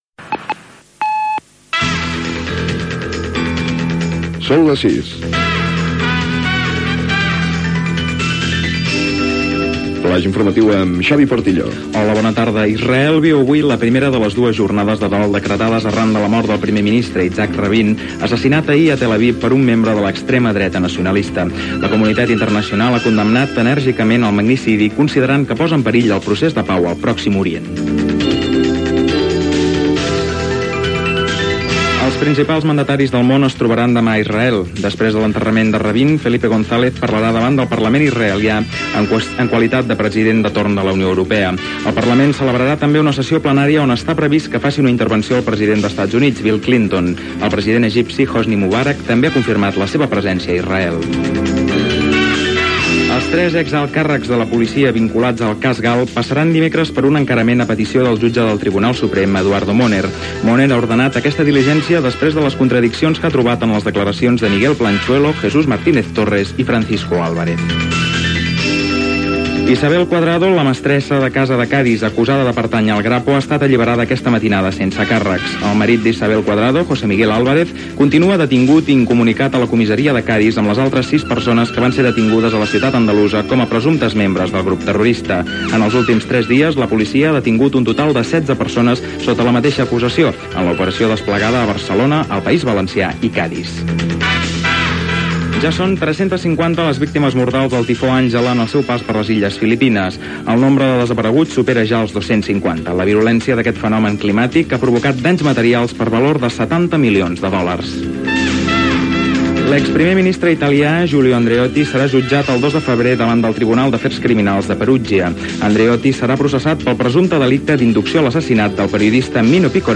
Senyals horaris i butlletí informatiu. Entre d'altres la mort de Yitshaq Rabín i la vaga dels pilots d'Iberia
Informatiu